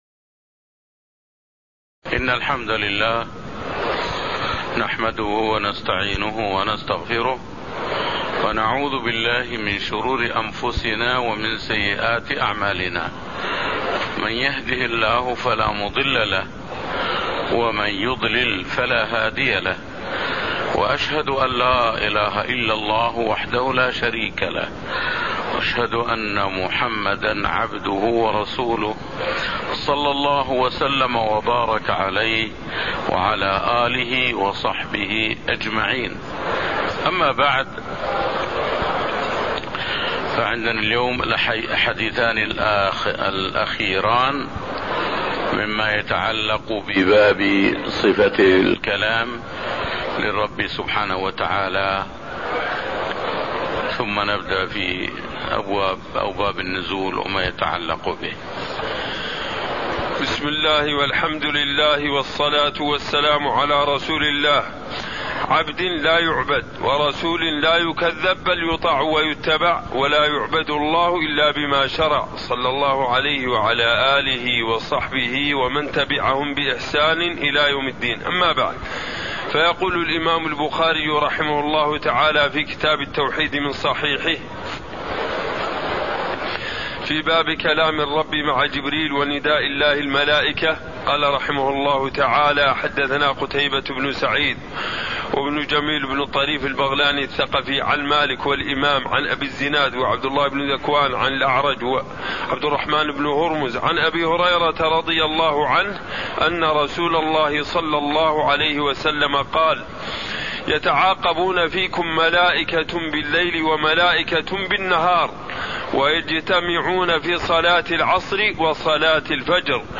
تاريخ النشر ١ جمادى الأولى ١٤٣٥ هـ المكان: المسجد النبوي الشيخ